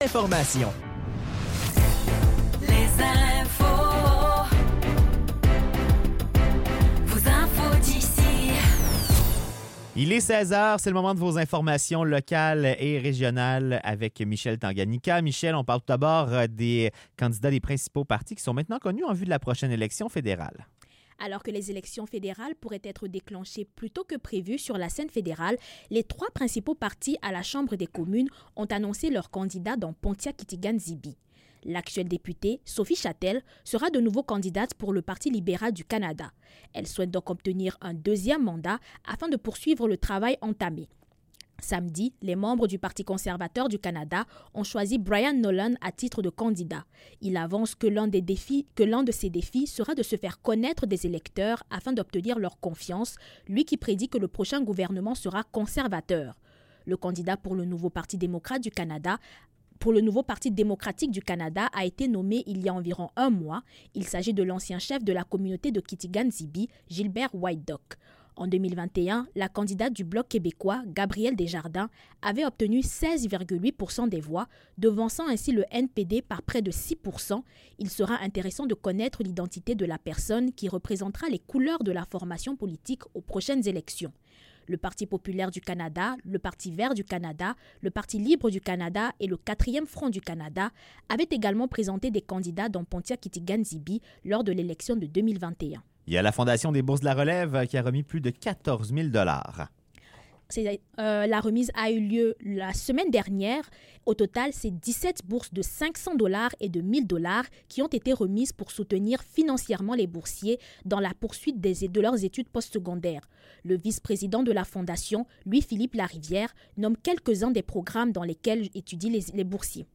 Nouvelles locales - 17 décembre 2024 - 16 h